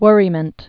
(wûrē-mənt, wŭr-)